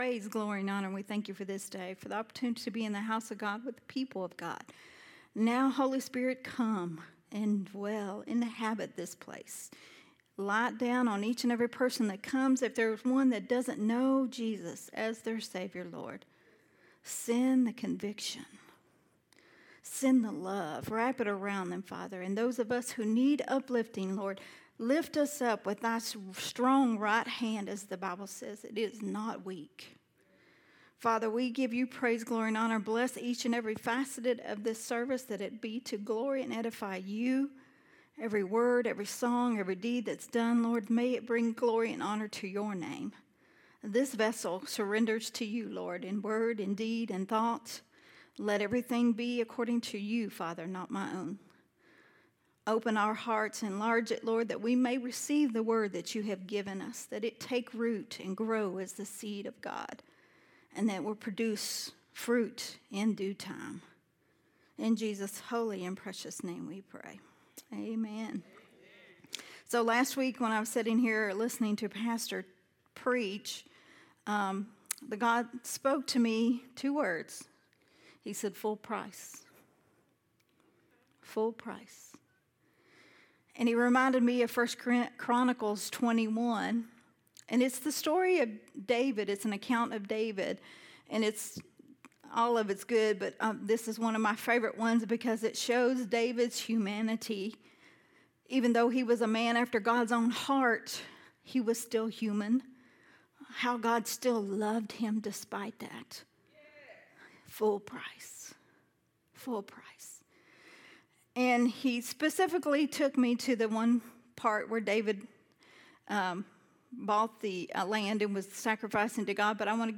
recorded at Unity Worship Center on 8/6/2023.